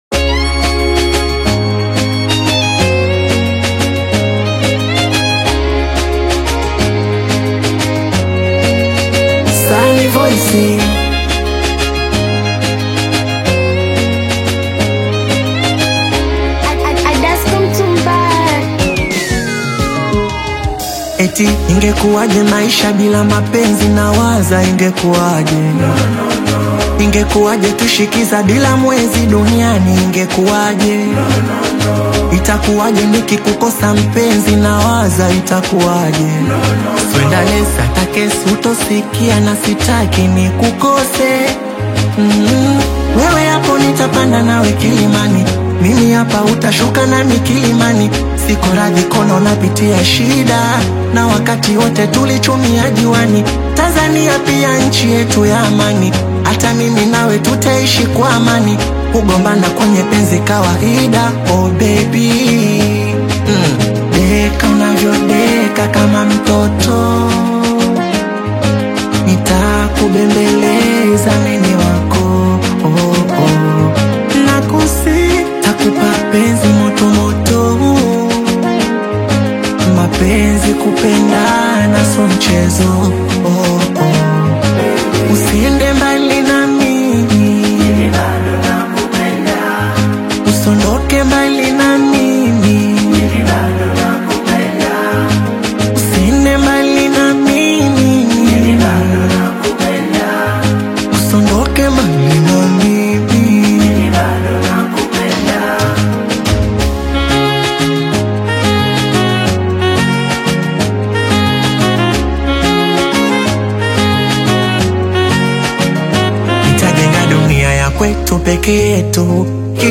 Genre: Singeli